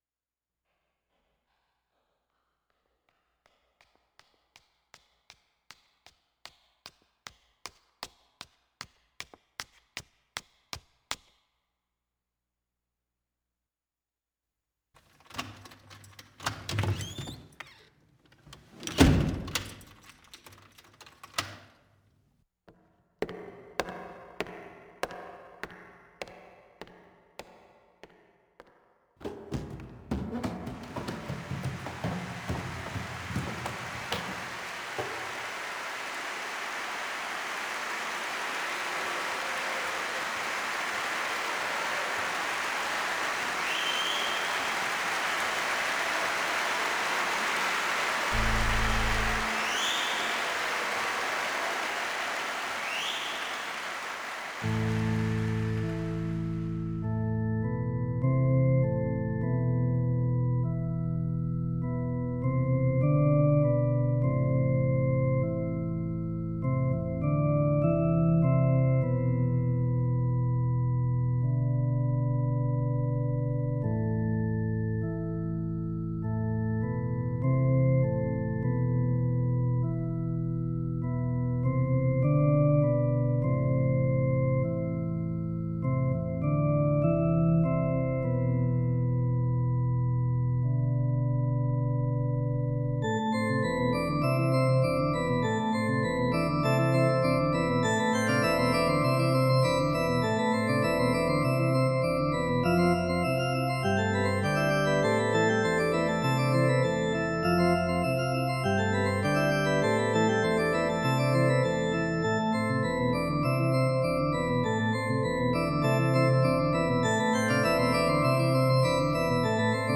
A minor
Instrumental music
computer
organ